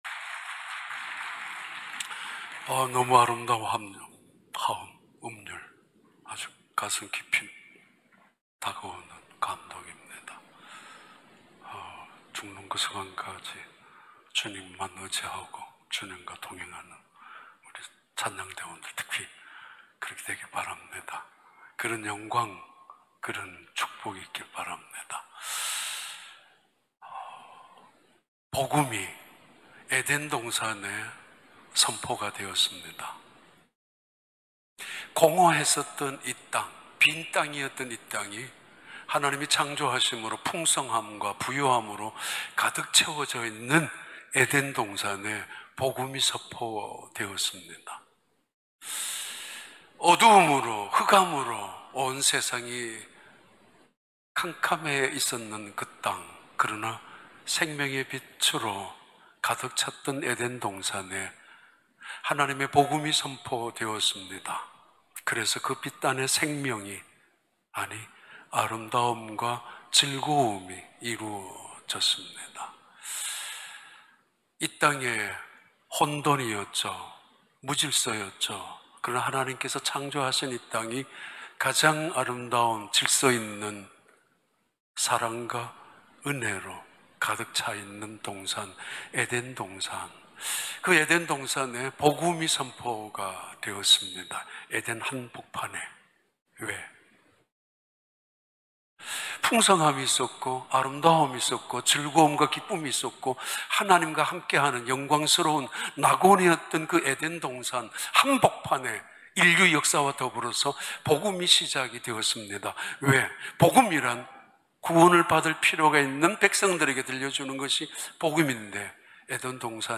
2021년 7월 18일 주일 4부 예배